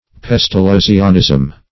Search Result for " pestalozzianism" : The Collaborative International Dictionary of English v.0.48: Pestalozzianism \Pes`ta*loz"zi*an*ism\, n. The system of education introduced by Pestalozzi.